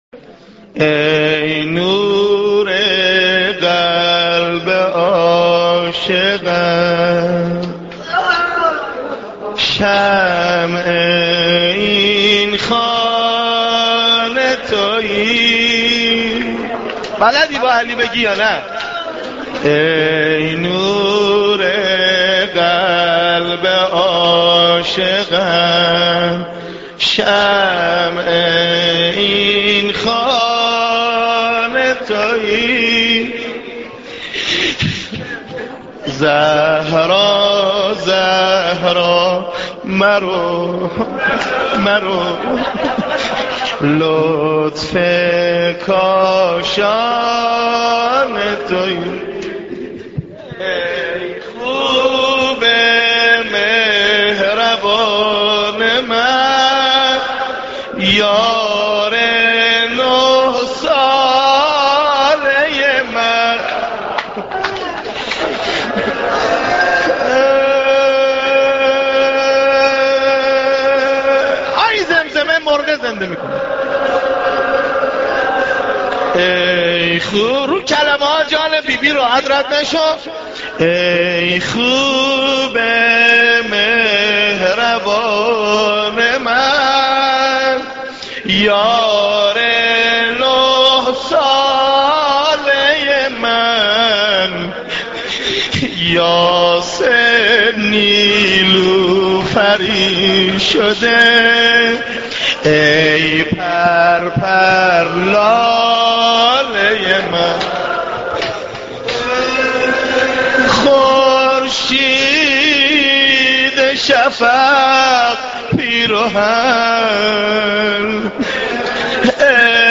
دانلود مداحی زهرا زهرا مرو مرولطف کاشانه تویی - دانلود ریمیکس و آهنگ جدید
مجلس نوحه‌خوانی برای شهادت حضرت زهرا مطهره(س)